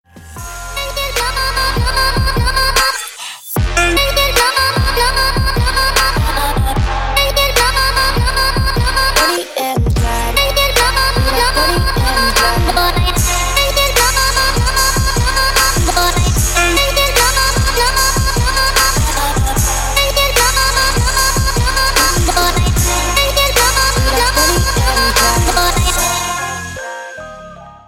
• Качество: 320, Stereo
мощные
женский голос
Electronic
Trap
качающие
Стиль: trap